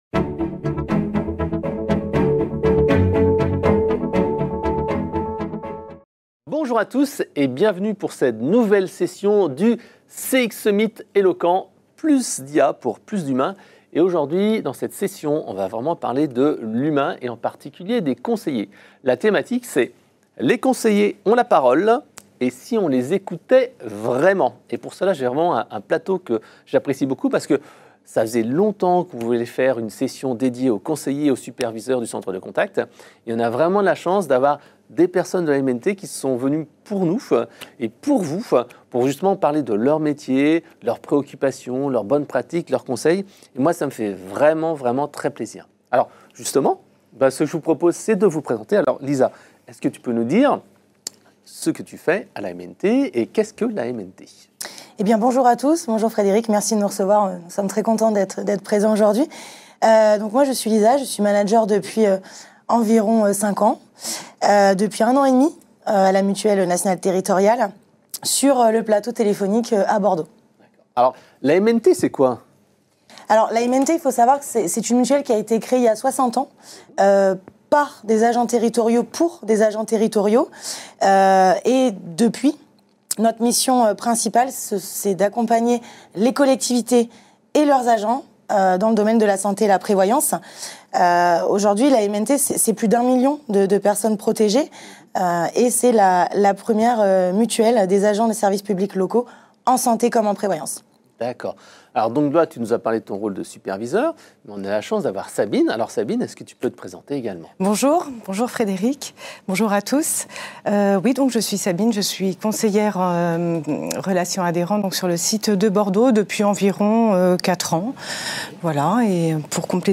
Dans cet atelier, nous leur donnons (enfin!) la parole : qu’est ce qui fait vraiment la différence lors des échanges avec les clients ? Qu’est-ce qui devrait changer dans la relation client, en termes d’organisation, de management, d’outils et d’IA ?